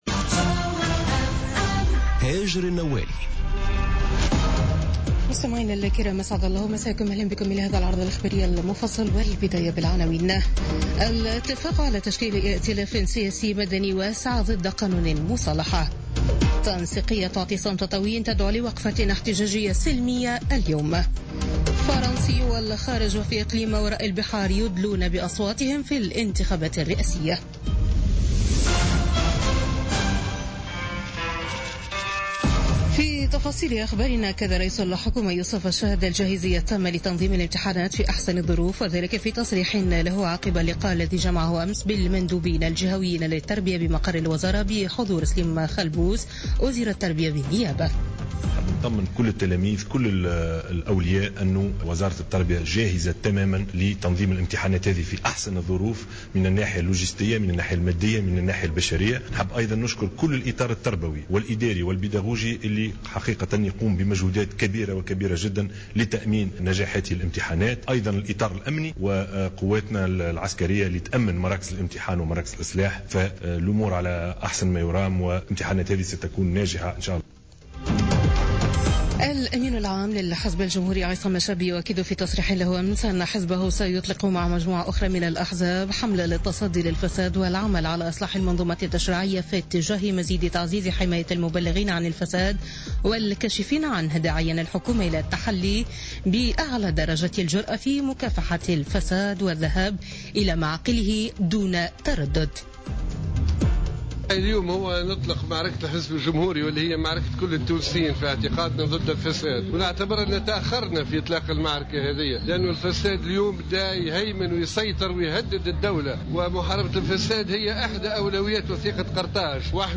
نشرة أخبار منتصف الليل ليوم الأحد 7 ماي 2017